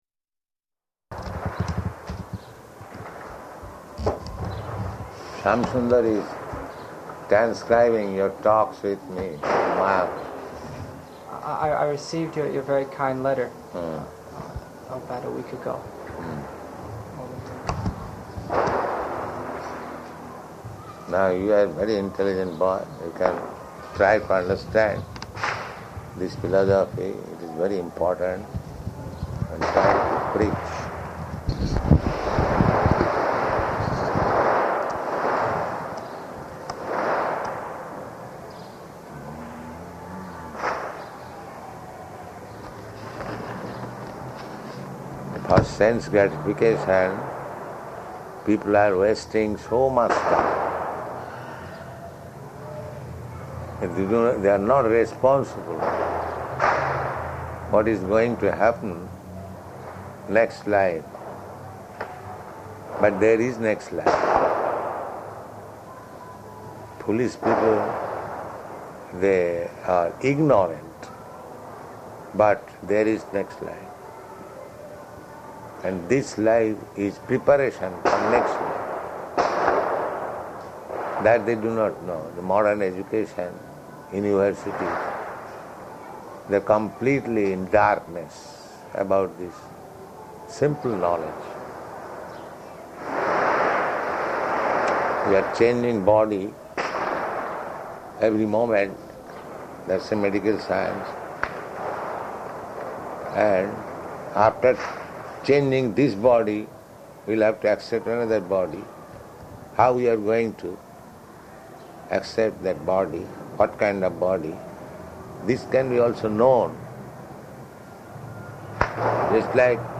Type: Conversation
Location: New York
[loud sounds of fireworks in background]